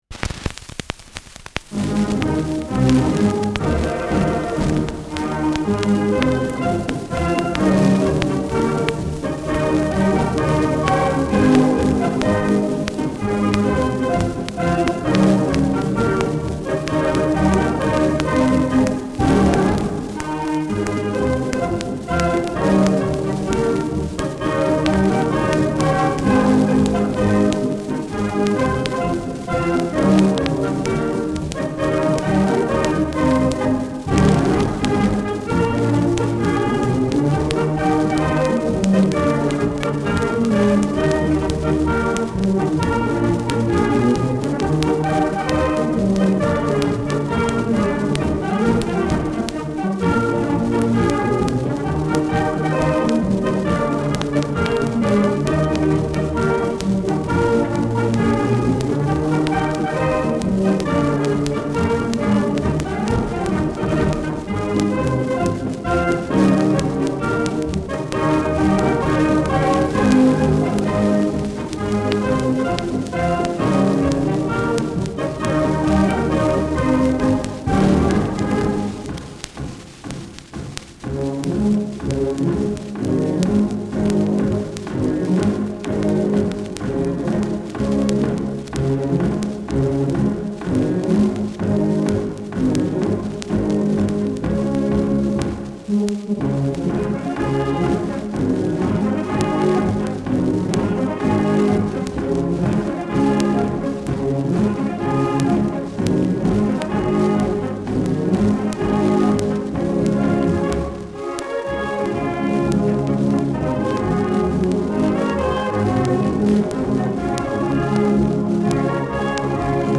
Schellackplatte
Orchester der Volkspolizei Berlin (Interpretation)